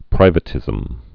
(prīvə-tĭzəm)